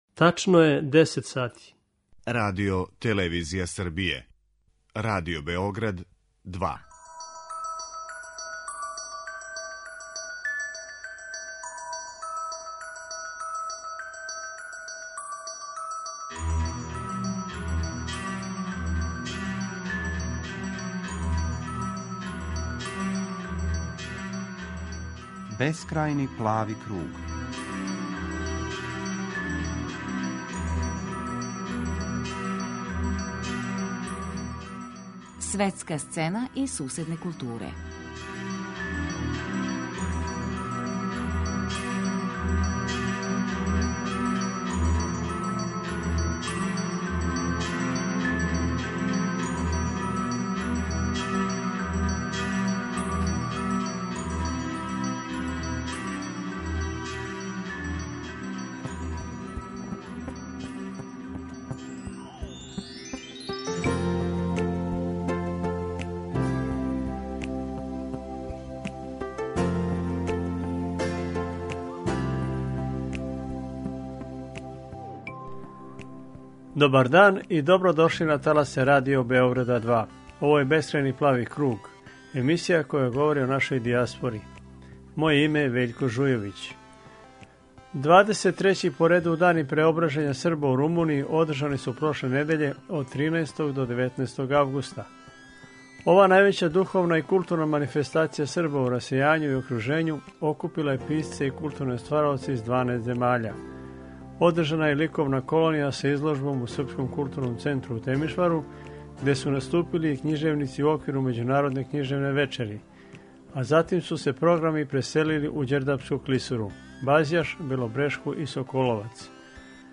Чућемо репротажу са ових догађаја.